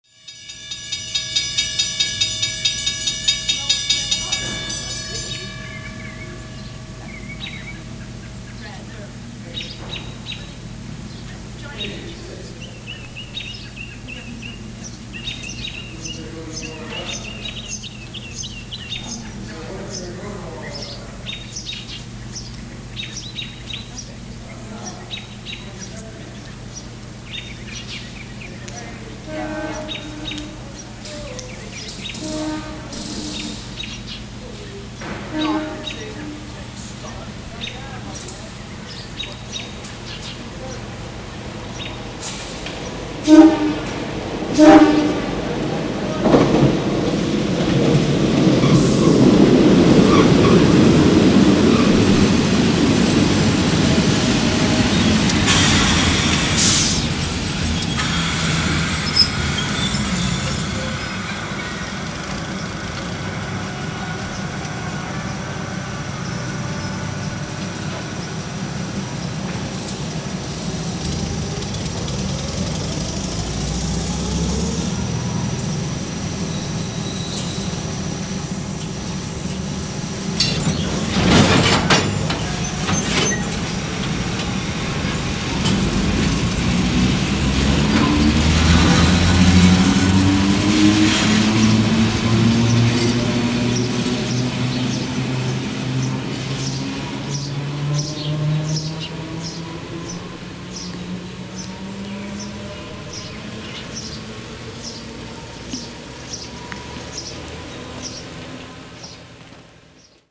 sample (3530K) of an MU approaching a station at a crossing, with the crossing bells going off, MU's approaching with horns blowing, dropping off passengers, and then accelerating with that classic MU "hum" - an amazing sound sample!